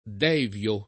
devio [ d $ v L o ]